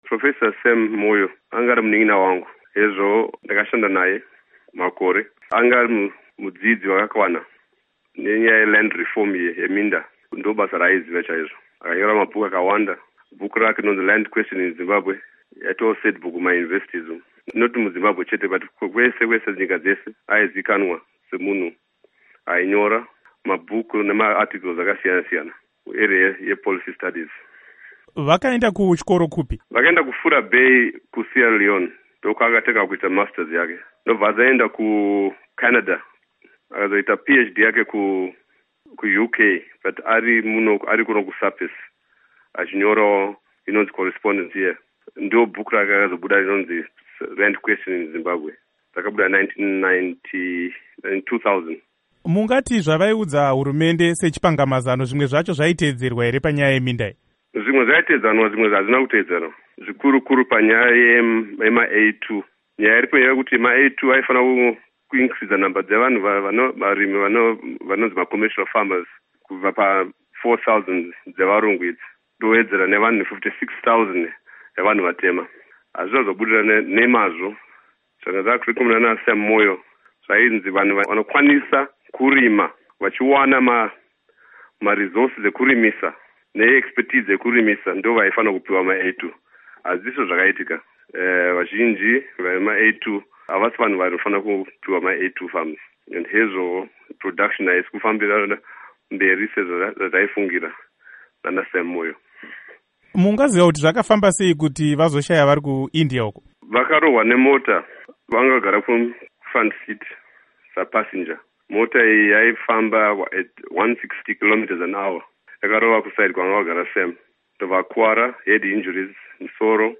Hurukuro naDoctor Ibbo Mandaza